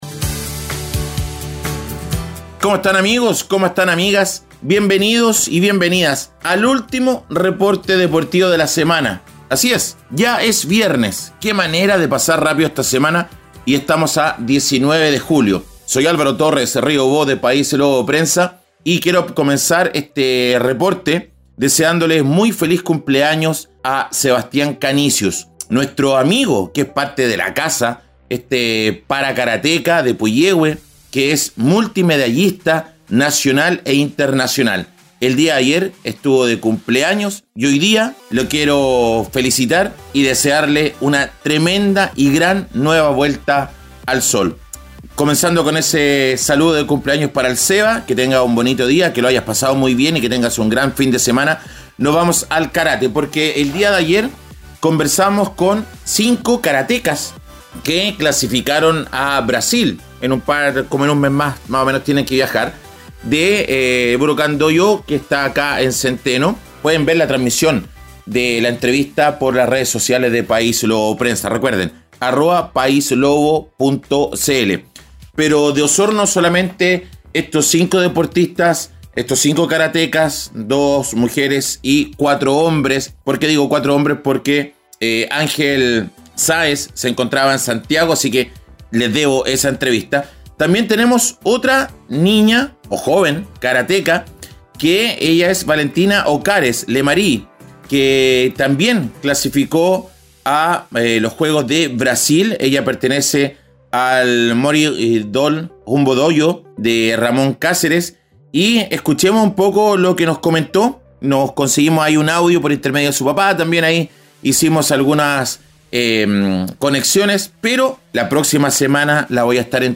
*Karate*: Entrevista a karatekas que van a Brasil.